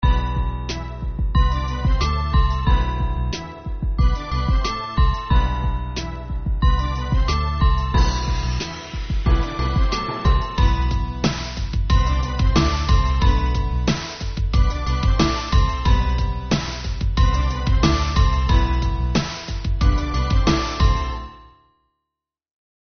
(инструментальный); темп (102); продолжительность (3:00)